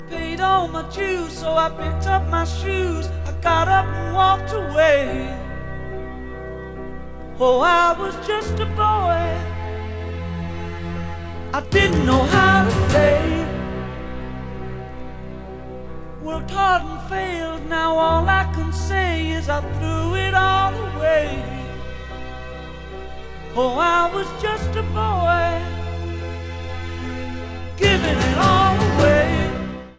Mono promo single version